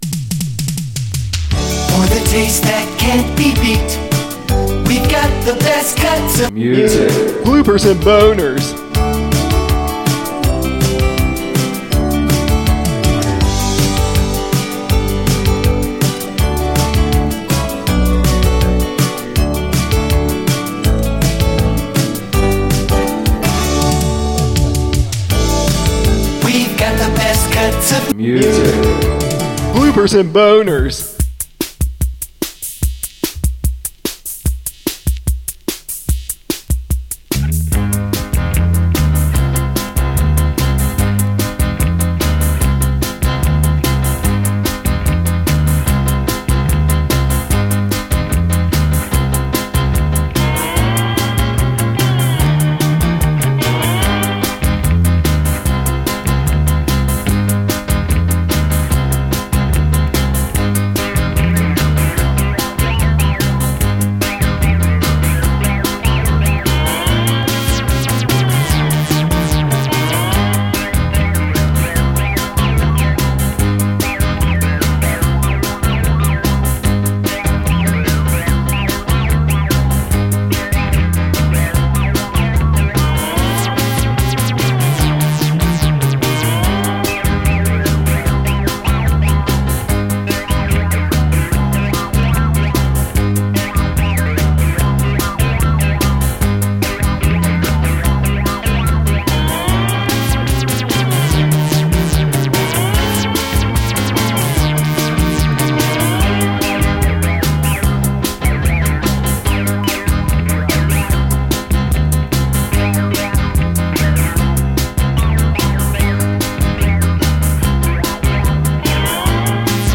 An episode chock full of hilarious blunders, awkward goofs, and silly bungles!